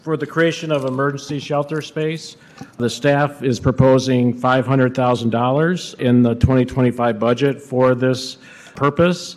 Kalamazoo city commissioners held a public hearing for their 2025 budget, announcing a late amendment to set aside money for the homeless.
City Manager Jim Ritsema announced that commissioners Qianna Decker and Stephanie Hoffman worked with staff to carve the funding out of their remaining ARPA dollars.